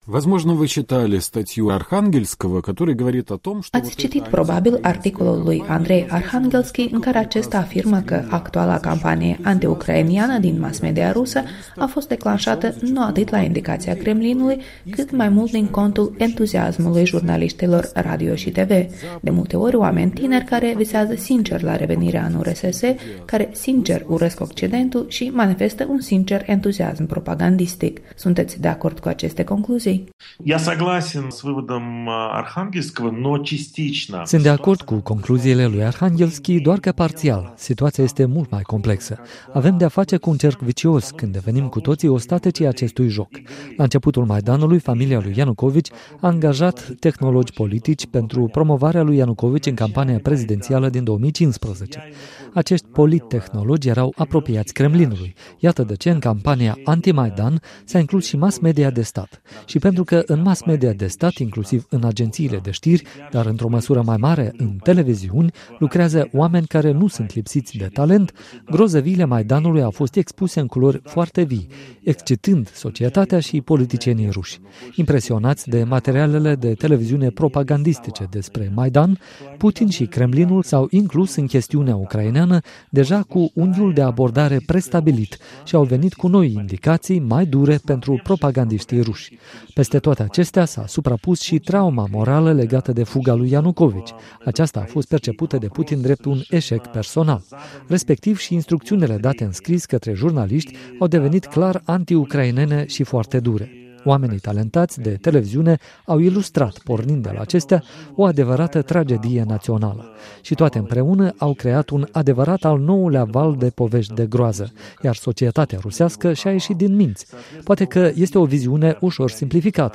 Interviu cu jurnalistul Pavel Șeremet